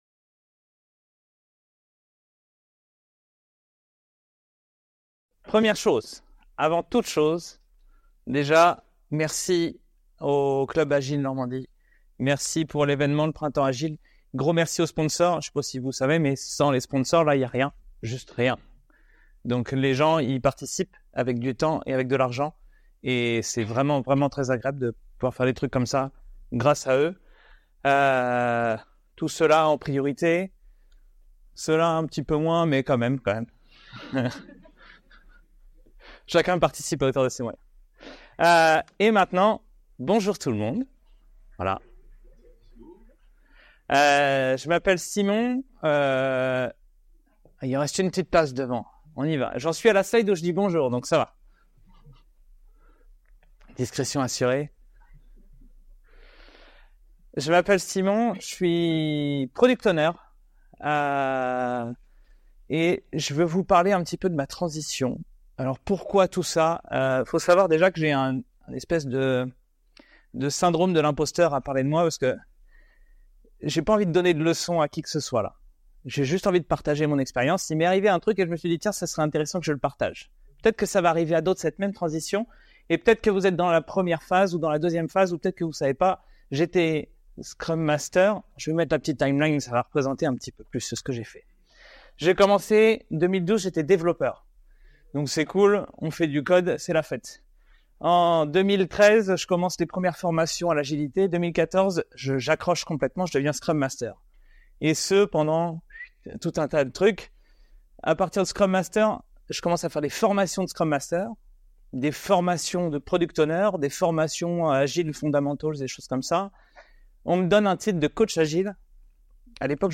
Le printemps agile 2024 fait son festival